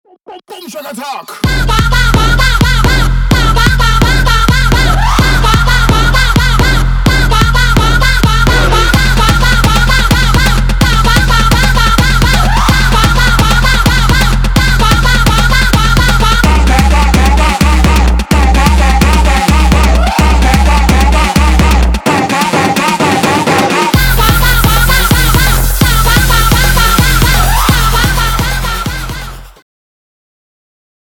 • Качество: 320, Stereo
громкие
мощные
EDM
забавный голос
Стиль: jungle terror